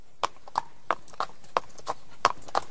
Horswalk.ogg